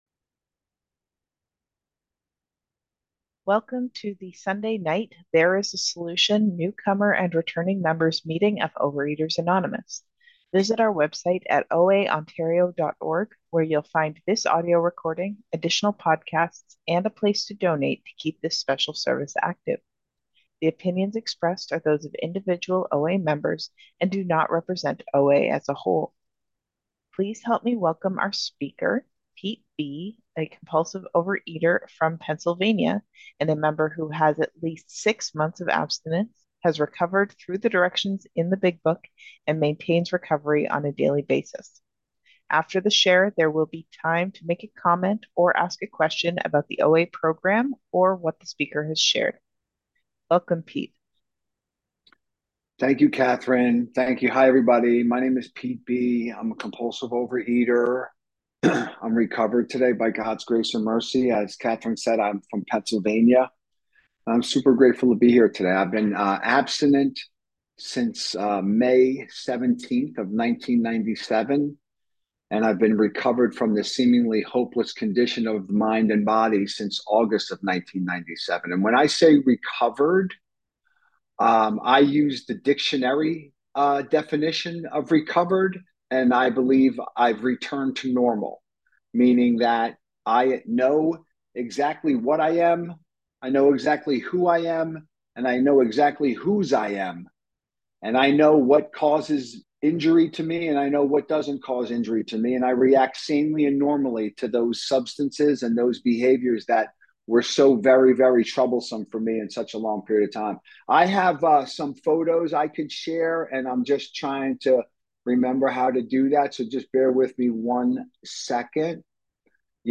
OA Newcomer Meeting
Speaker Files